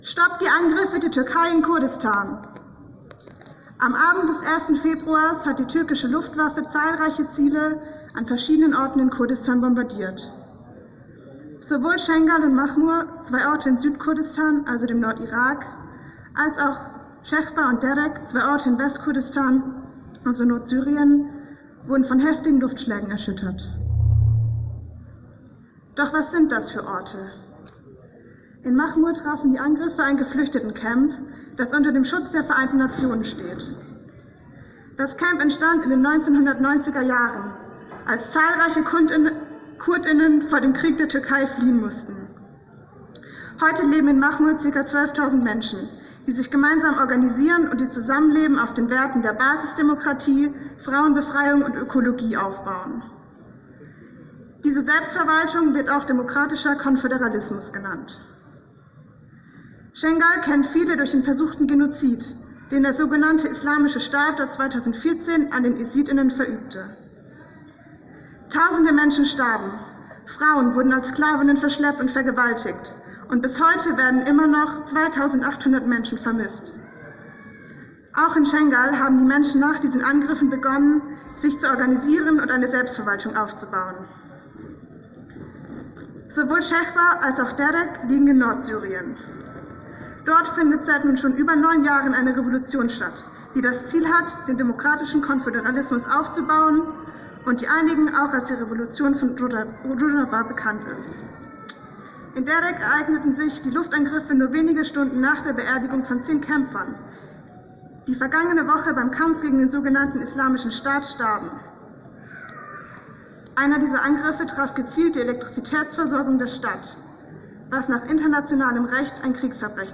erster Redebeitrag der Kundgebung